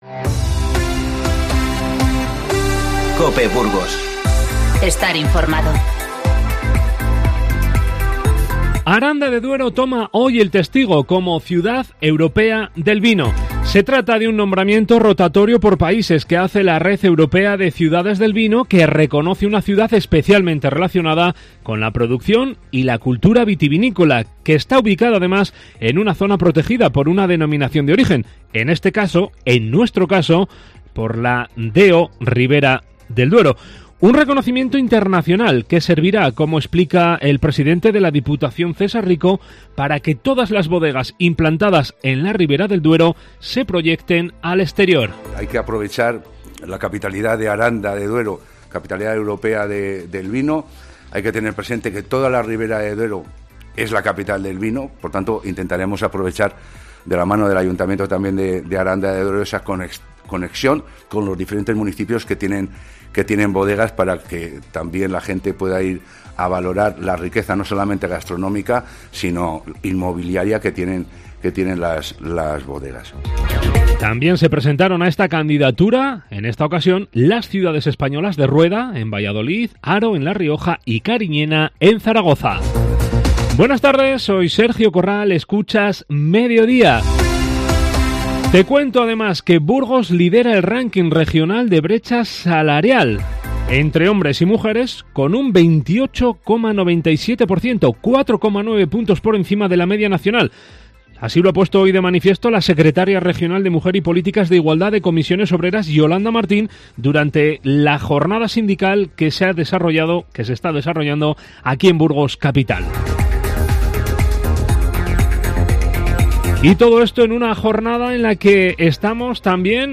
Informativo 07-02-20